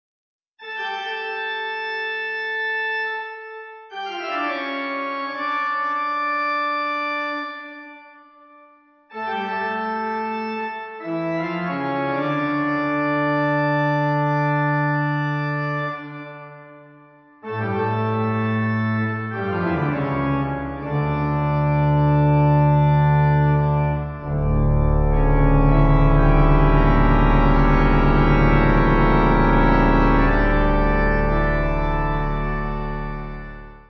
Easy Listening   Dm